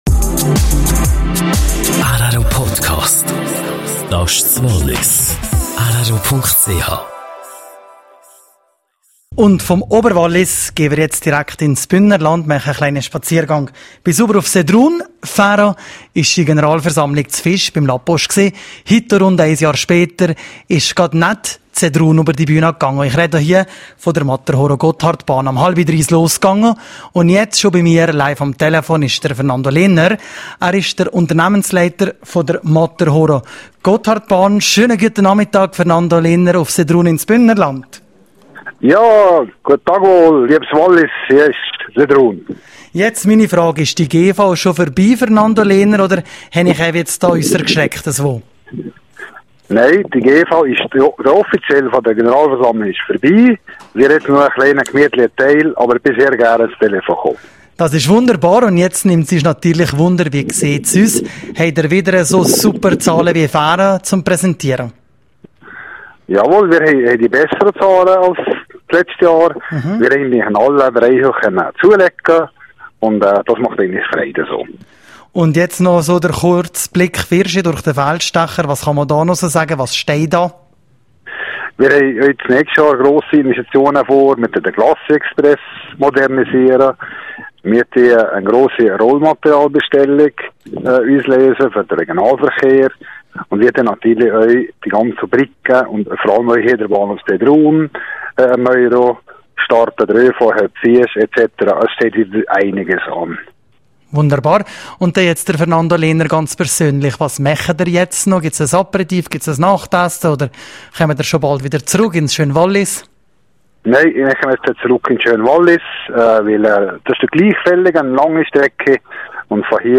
GV Matterhorn Gotthard Bahn: Interview